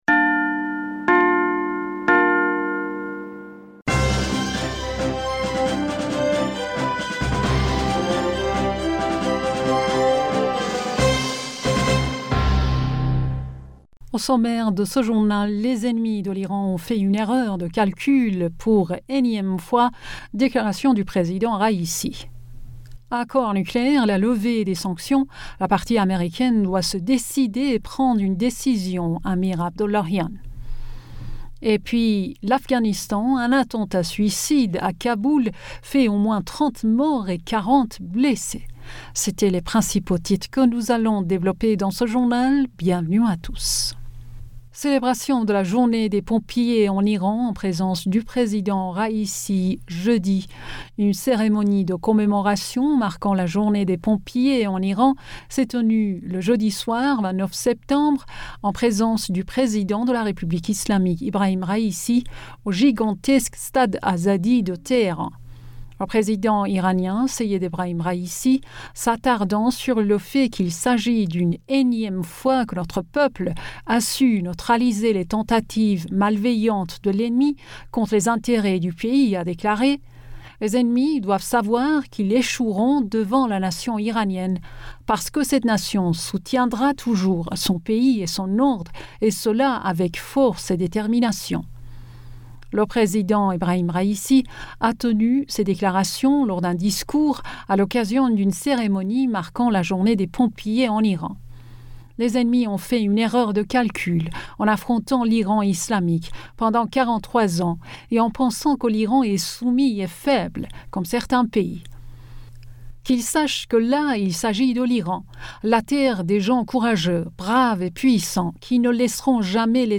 Bulletin d'information Du 30 Septembre